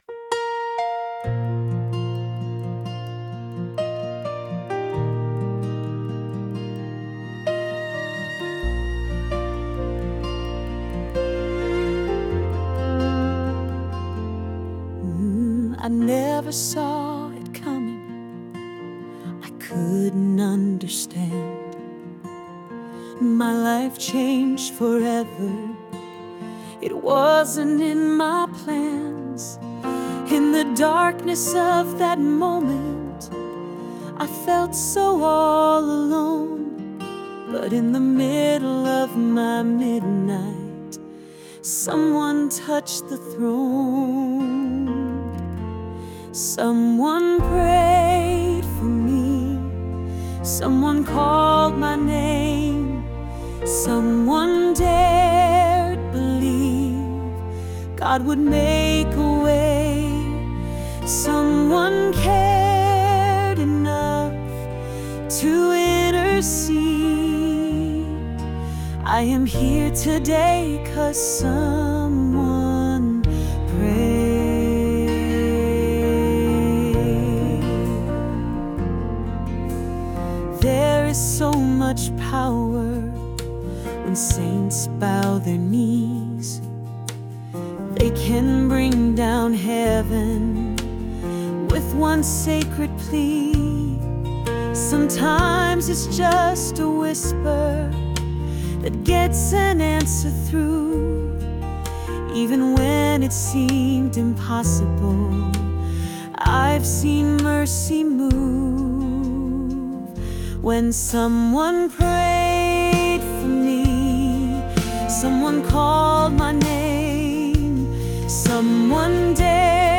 Female Demo